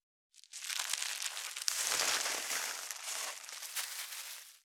656ゴミ袋,スーパーの袋,袋,買い出しの音,ゴミ出しの音,袋を運ぶ音,
効果音